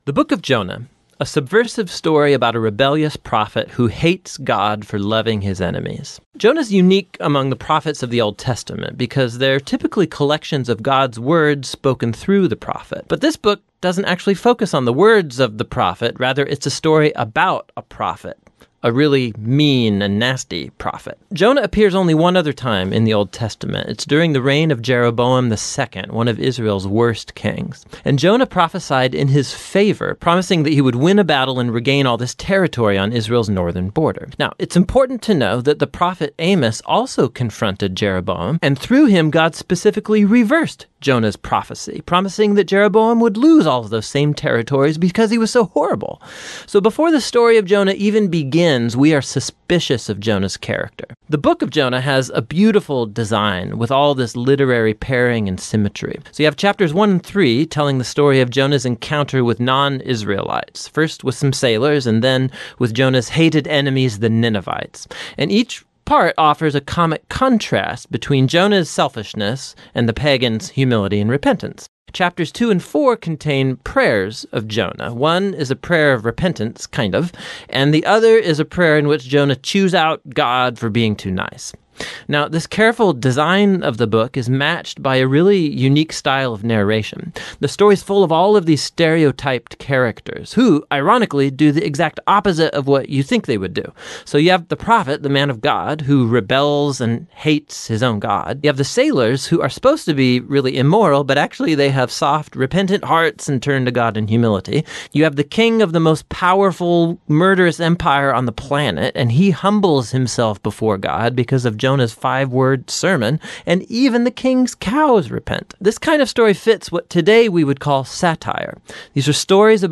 This sermon kicks off our summer series exploring the Book of Jonah, titled "Swallowed up by Mercy." This powerful message delves into God's relentless pursuit of rebels with His limitless mercy, as seen through Jonah’s story, and challenges us to respond to God’s call in our own lives.